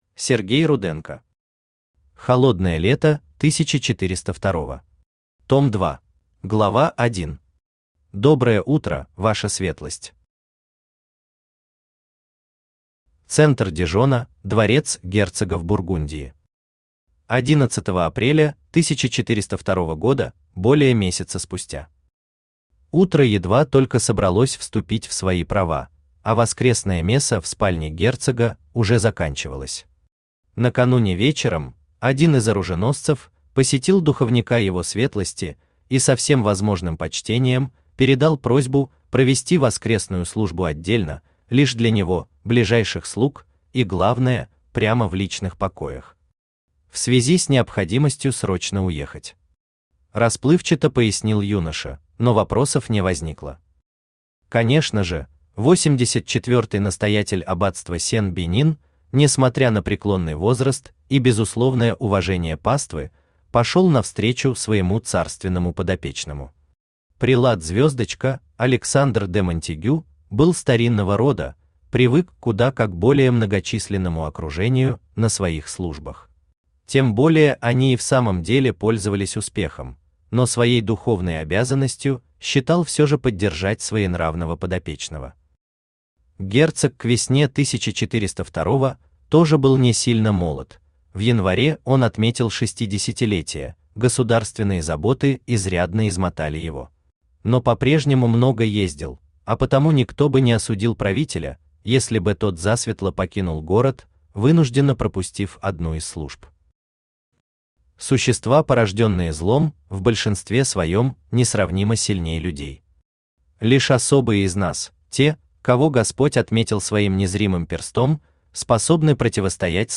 Аудиокнига Холодное лето 1402-го. Том 2 | Библиотека аудиокниг
Том 2 Автор Сергей Владимирович Руденко Читает аудиокнигу Авточтец ЛитРес.